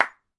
擊掌
描述：高五的声音。适用于视频游戏或视频中的声音效果。
标签： 鼓掌 手钳 冲击
声道立体声